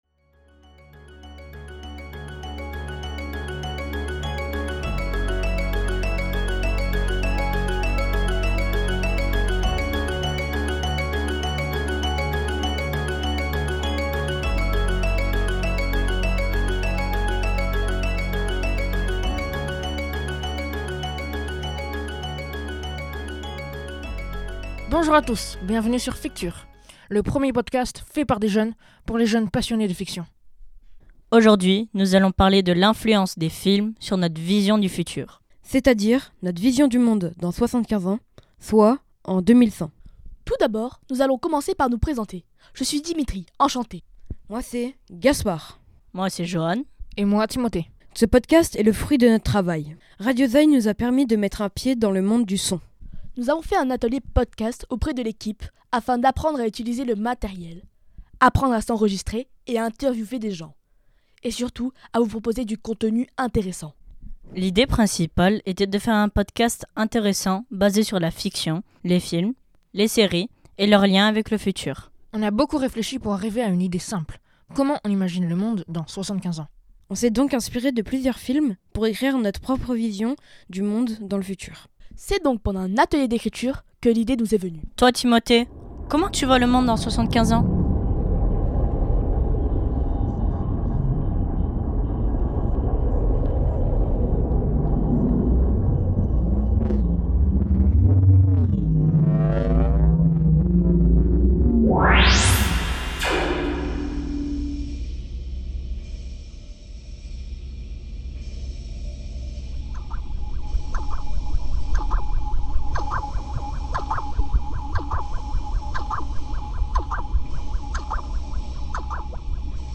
Dans ce podcast, discussion, ITW et critiques sont au programme.